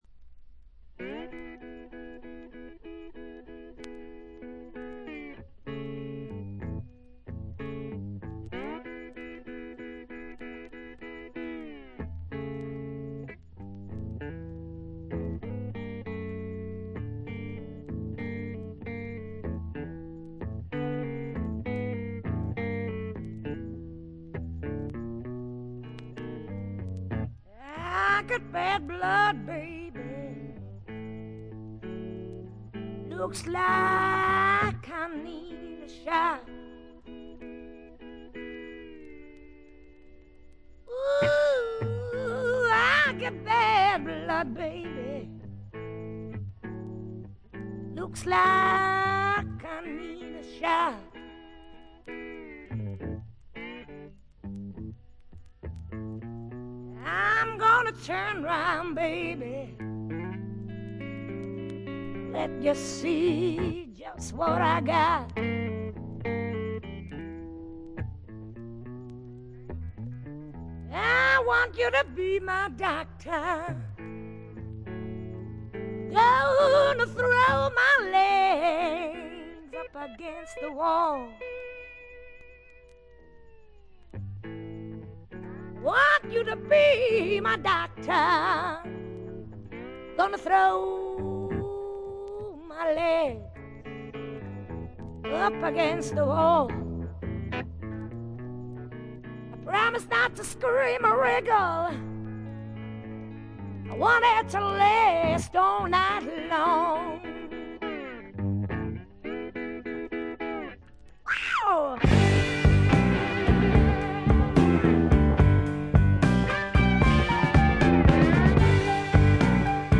重厚なスワンプロックの大傑作です。
試聴曲は現品からの取り込み音源です。